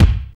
SWING BD 6.wav